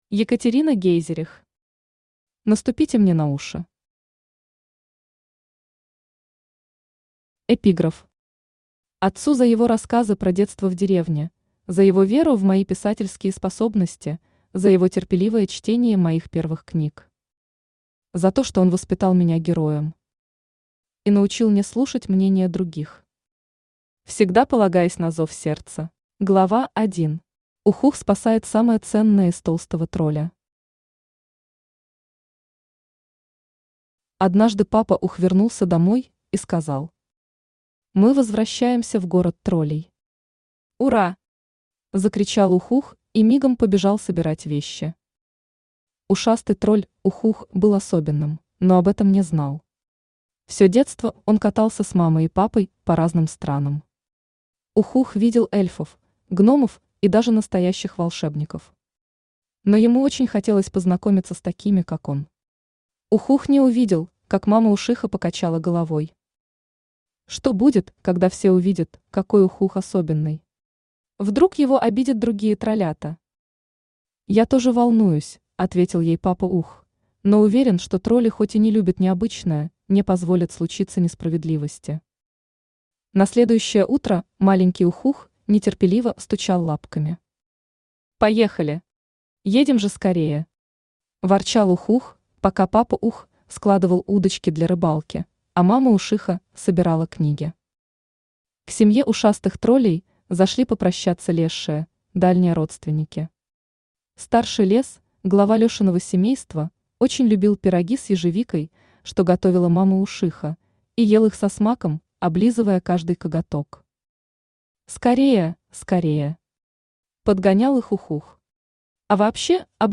Аудиокнига Наступите мне на уши | Библиотека аудиокниг
Aудиокнига Наступите мне на уши Автор Екатерина Гейзерих Читает аудиокнигу Авточтец ЛитРес.